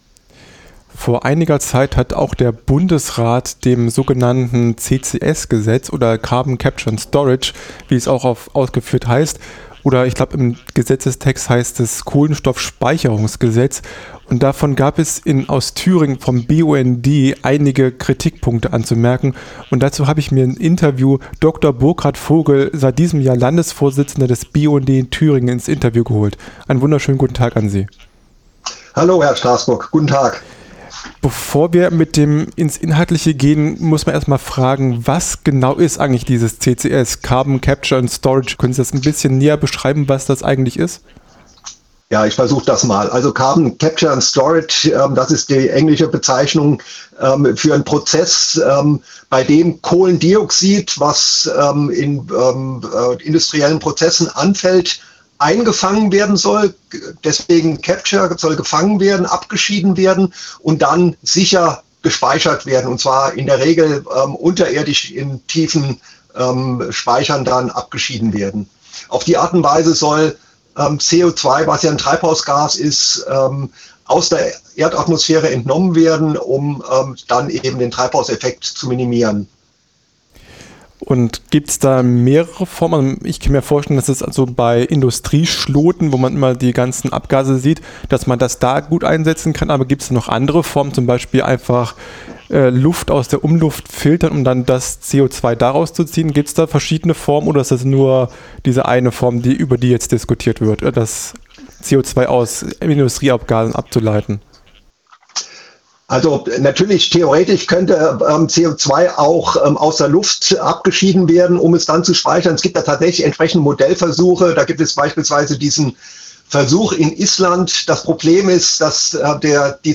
CCS - Notwendige Klimatechnologie oder Subvention für die fossile Wirtschaft - Ein Interview